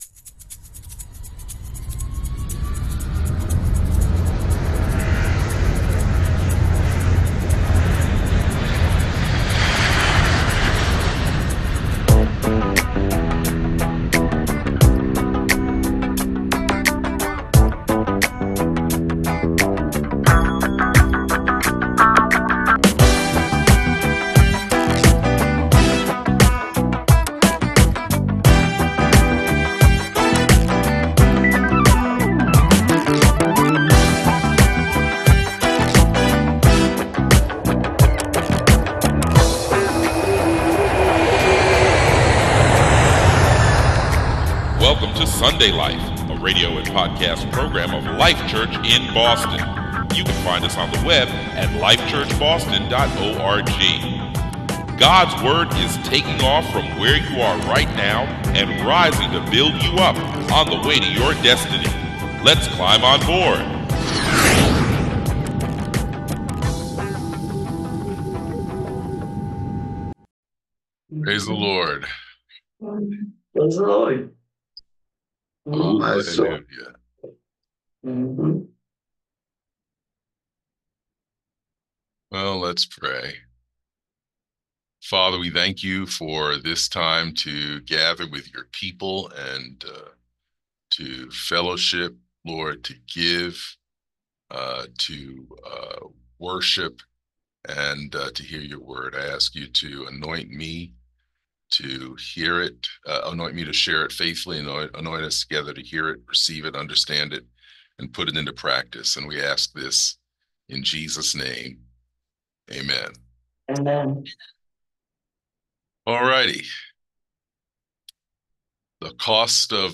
Sunday Life (sermon podcast)
Sunday July 21, 2024 10:30 A.M. Message from Life Church Boston